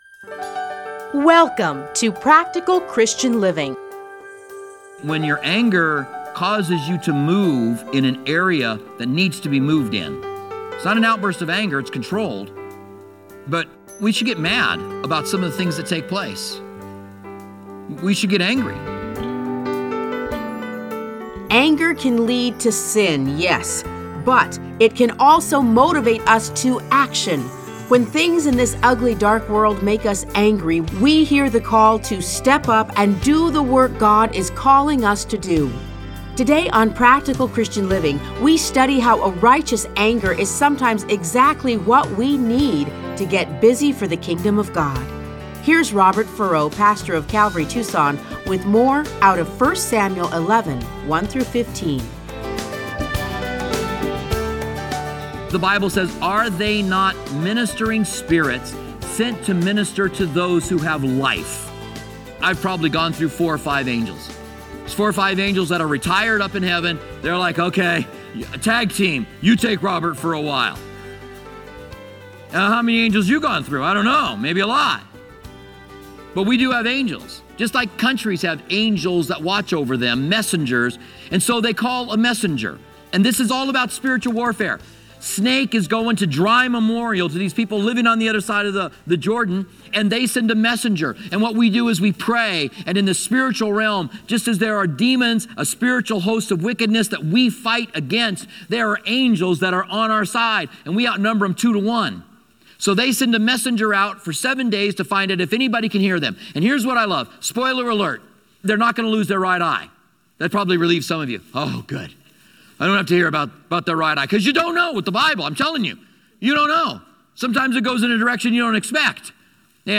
Listen to a teaching from 1 Samuel 11:1-15.